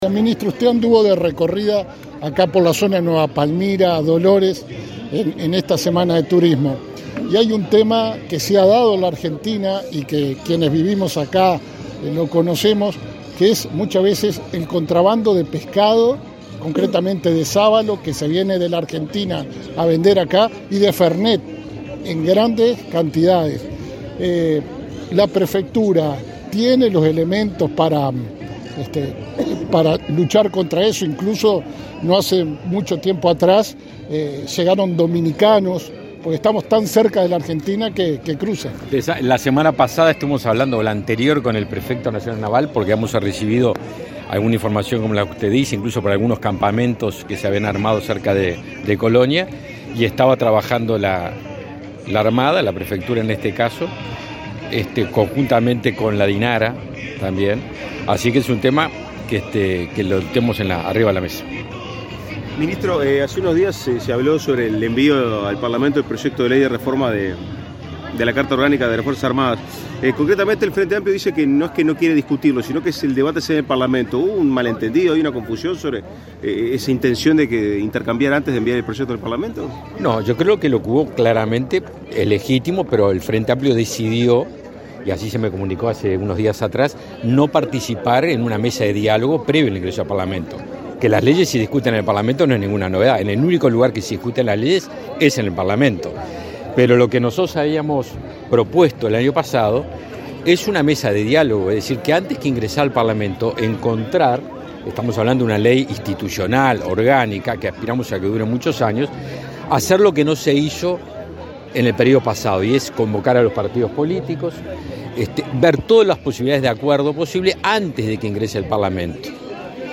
Declaraciones a la prensa del ministro Javier García
Declaraciones a la prensa del ministro Javier García 19/04/2022 Compartir Facebook X Copiar enlace WhatsApp LinkedIn Este martes 19, el ministro de Defensa Nacional, Javier García, participó del acto aniversario del desembarco de los 33 Orientales en la playa de la Agraciada, departamento de Soriano, y, luego, dialogó con la prensa.